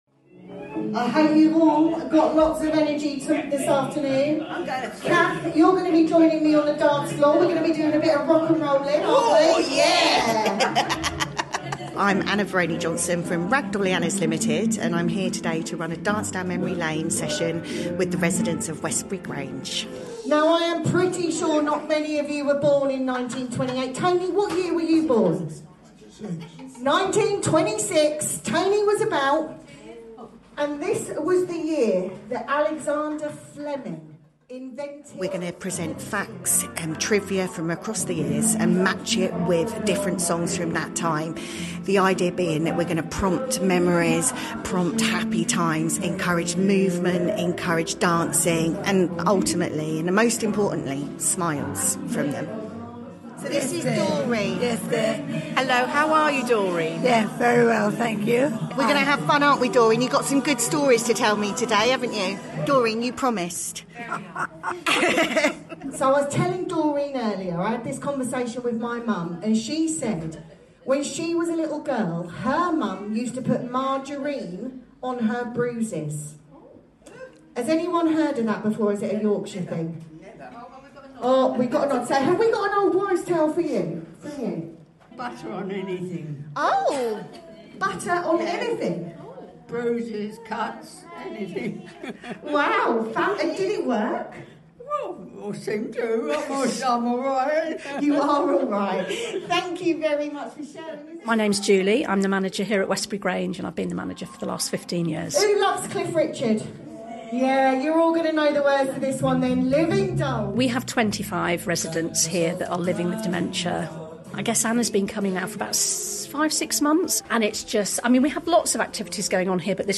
Recorded Live for Dementia Awareness Week
We were honoured to welcome BBC Three Counties Radio to one of our care home sessions during Memories and Dementia Awareness Week.
They recorded a live Dance Down Memory Lane session, capturing the joy, music, laughter, and meaningful moments shared between residents and our team.